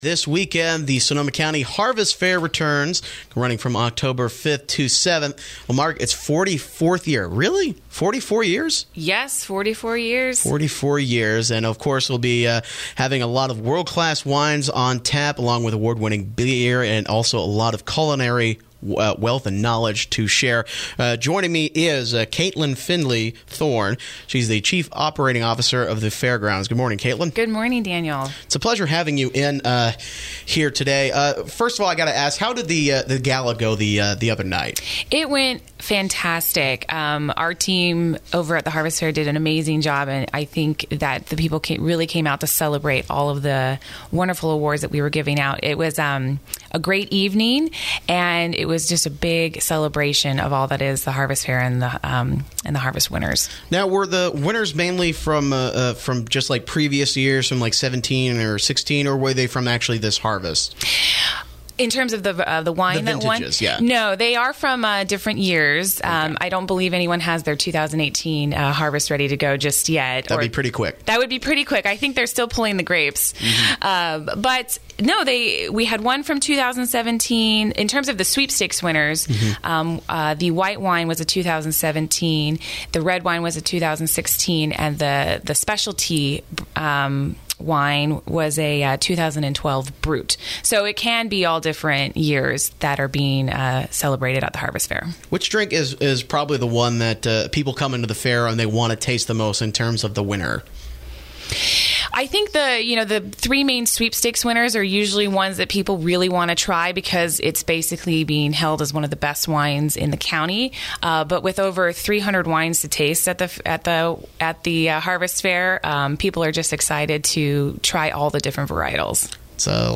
INTERVIEW: The 44th Annual Sonoma County Harvest Fair Arrives This Weekend